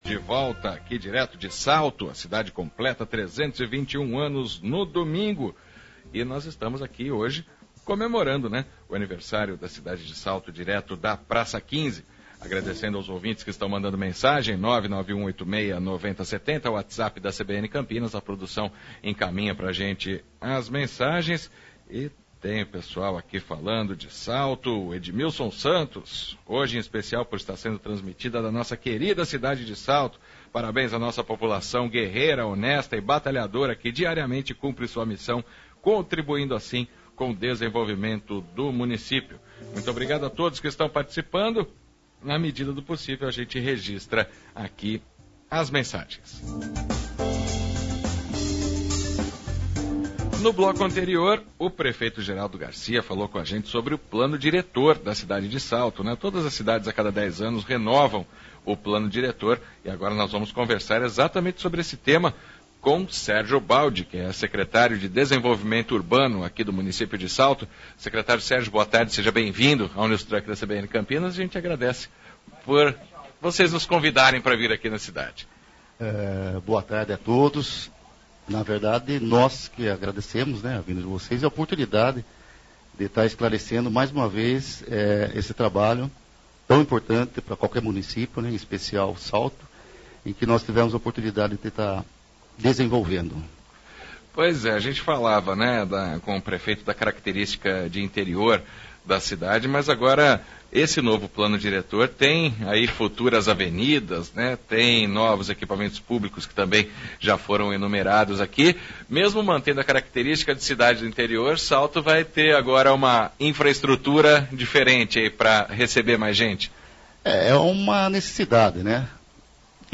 Secretário do Desenvolvimento Urbano de Salto, Sérgio Baldi fala sobre revisão do plano diretor da cidade - CBN Campinas 99,1 FM